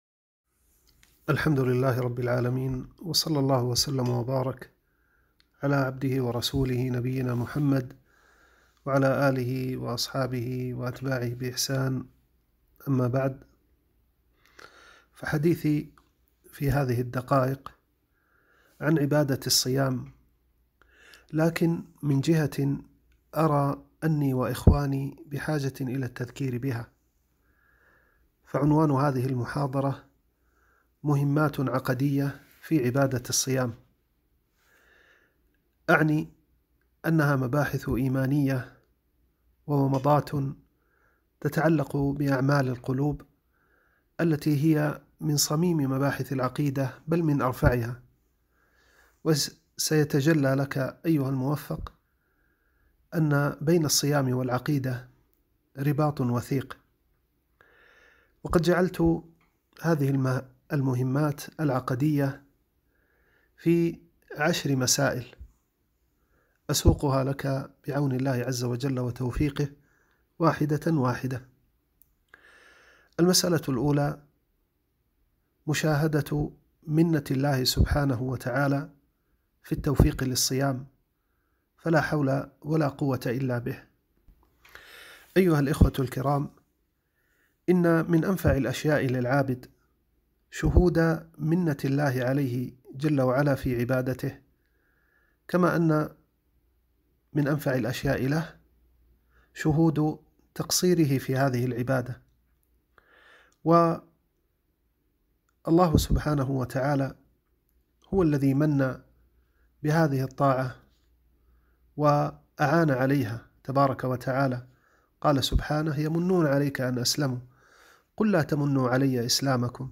▪ المحاضرة صوتية : [ الموقع الرسمي ] [ يوتيوب ] [ القطوف ]